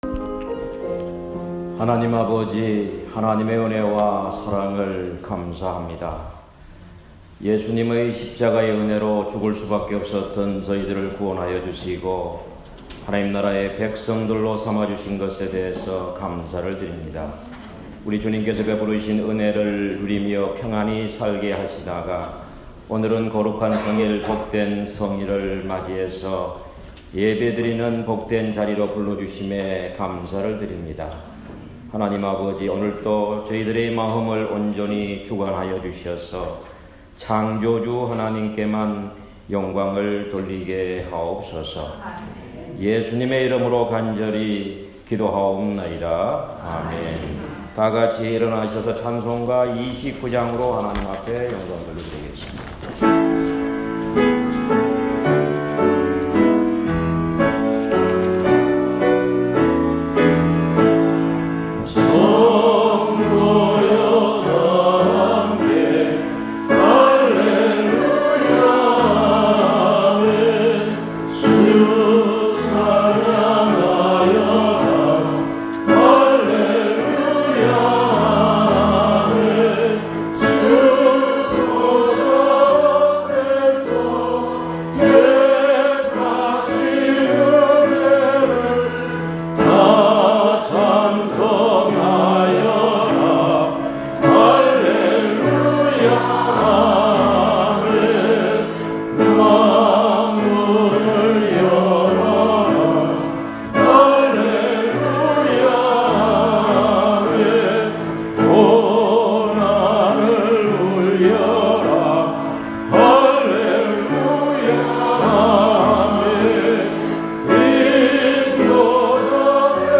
로마서 16:1-20 제목: 하나님이 원하시는 일 설교